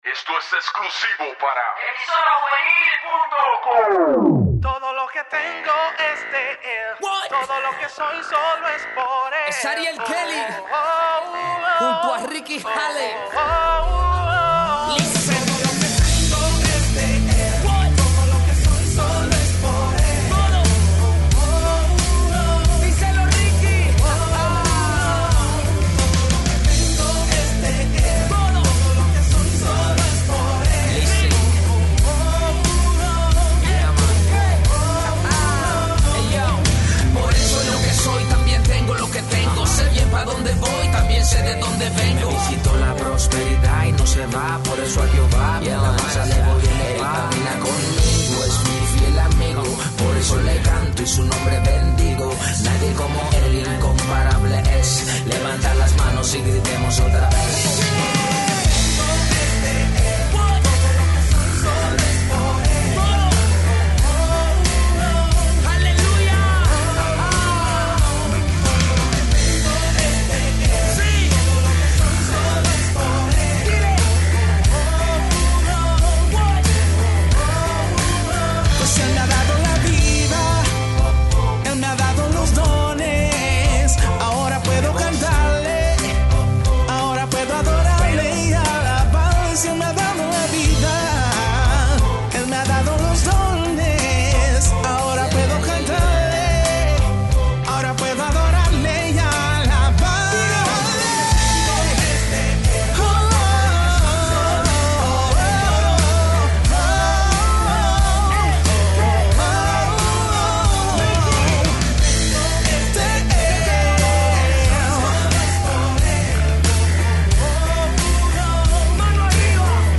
Música Cristiana
música urbana